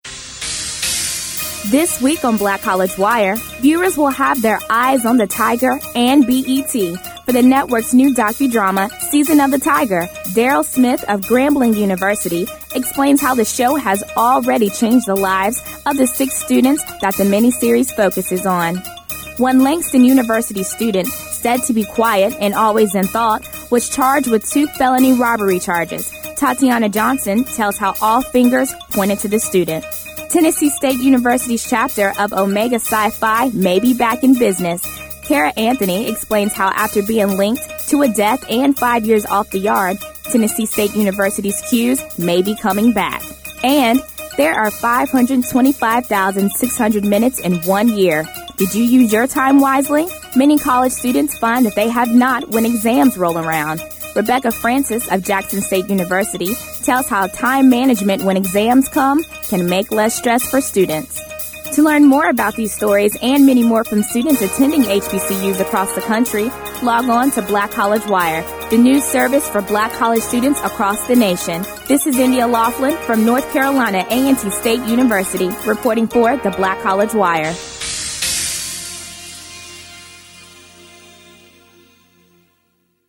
Audio news summary for the week of